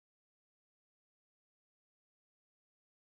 cisza.mp3